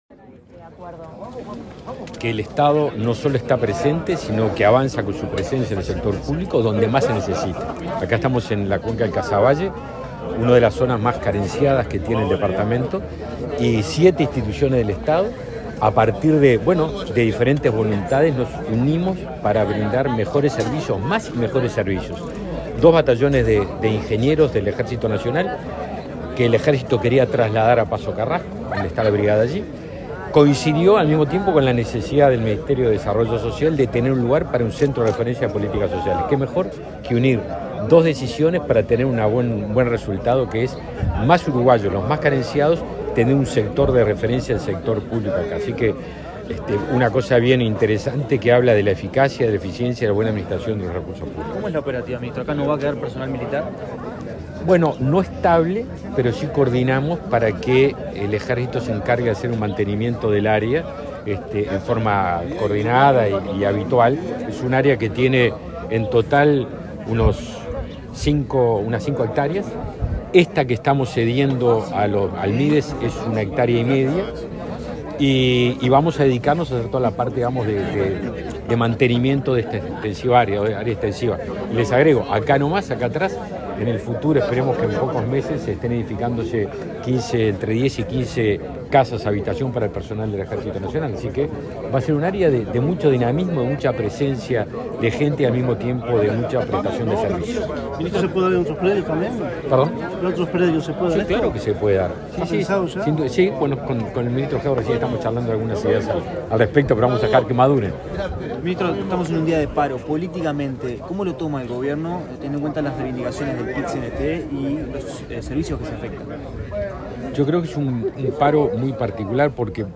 Declaraciones del ministro de Defensa Nacional, Javier García
Declaraciones del ministro de Defensa Nacional, Javier García 15/09/2022 Compartir Facebook X Copiar enlace WhatsApp LinkedIn El titular del Ministerio de Defensa Nacional, Javier García, participó en Montevideo en la firma para la instalación de un nuevo centro de referencia en políticas sociales y luego dialogó con la prensa.